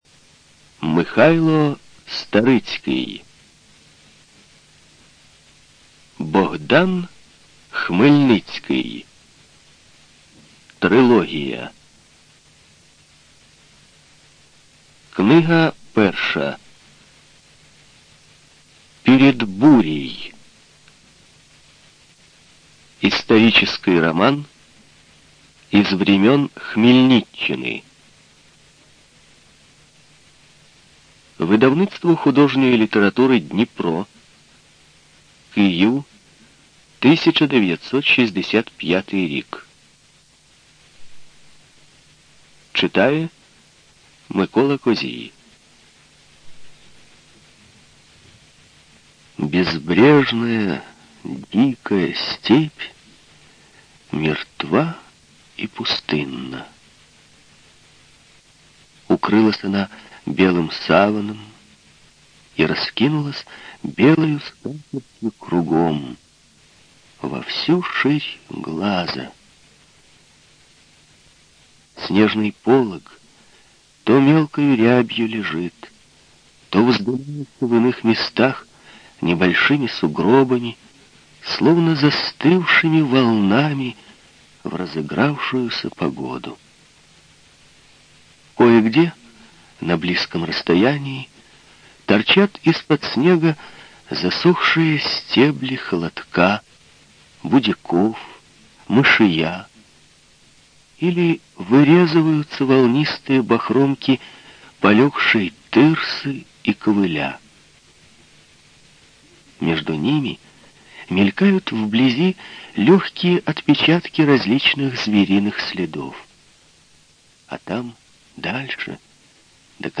Студия звукозаписиРеспубликанский дом звукозаписи и печати УТОС